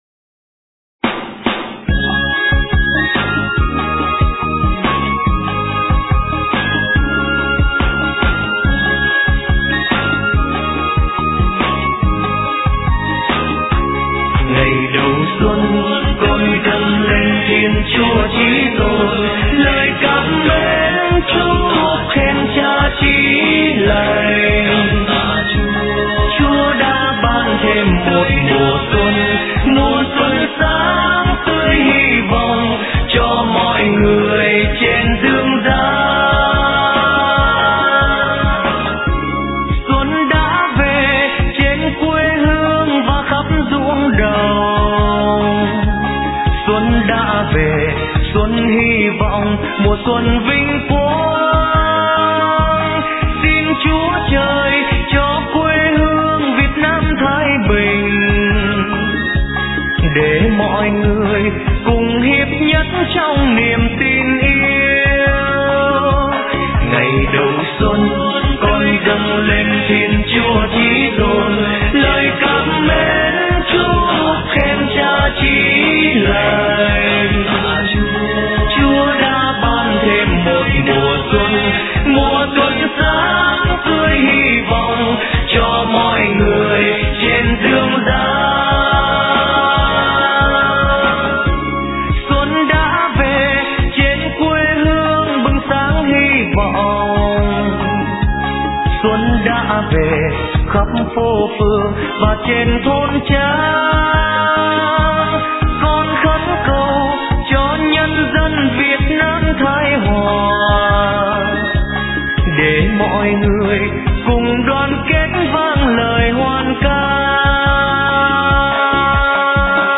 * Thể loại: Mừng Xuân